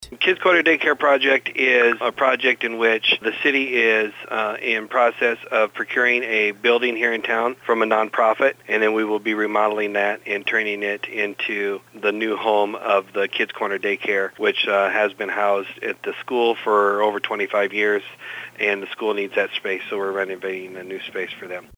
Clarion -A daycare center in Clarion is getting a new home. Here’s Clarion City Administrator Clint Middleton on the project.